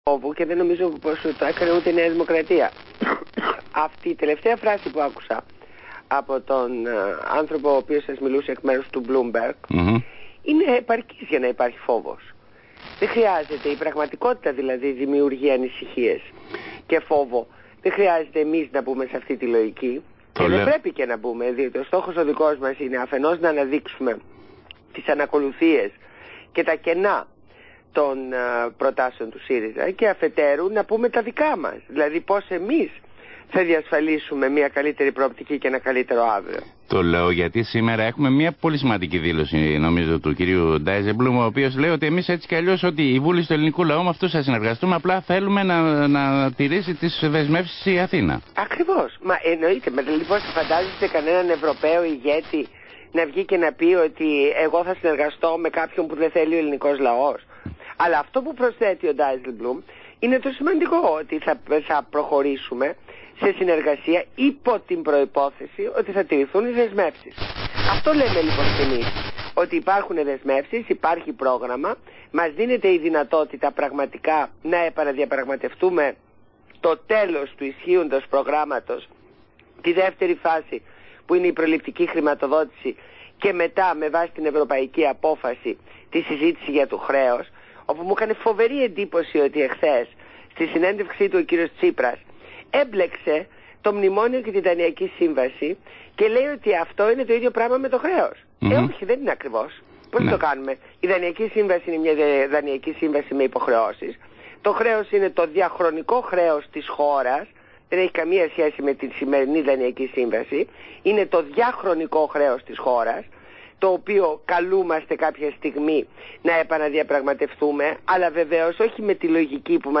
Συνέντευξη Ν. Μπακογιάννη στον Αθήνα 9,84